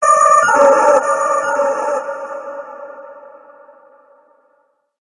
Cri de Papilusion Gigamax dans Pokémon HOME.
Cri_0012_Gigamax_HOME.ogg